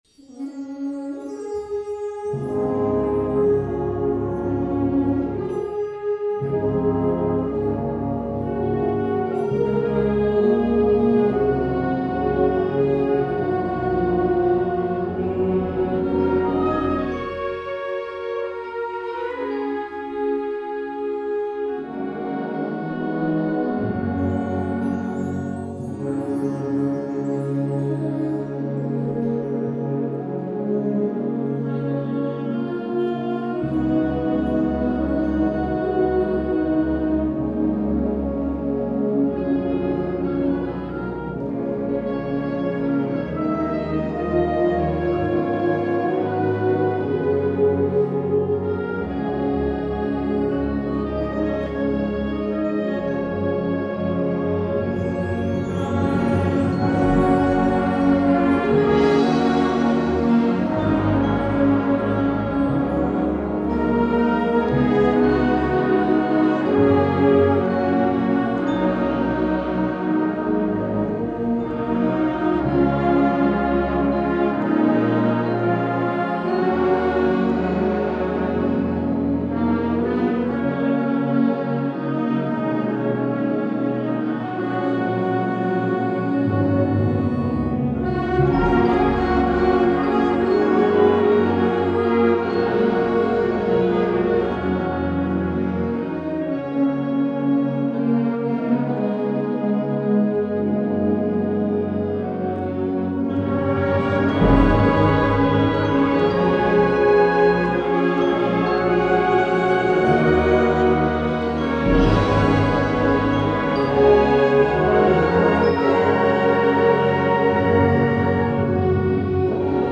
2011 Winter Concert